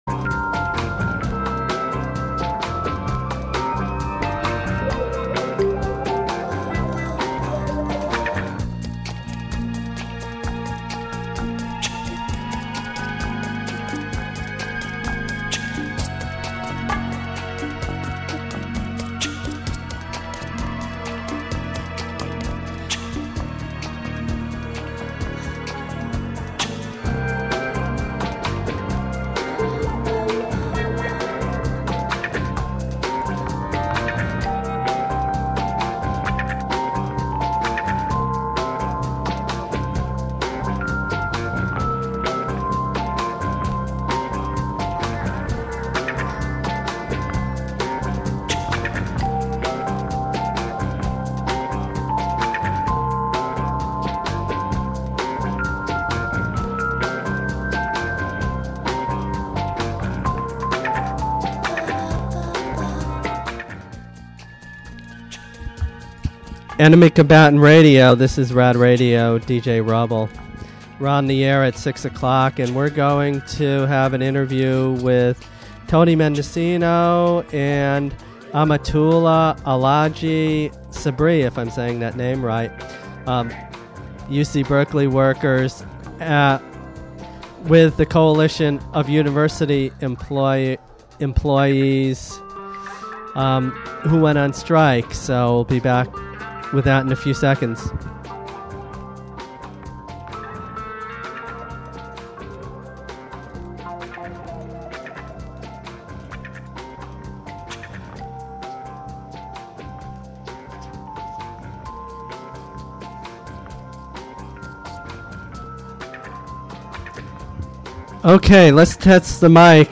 Interview with U.C. Berkeley CUE Workers on recent 3-day strike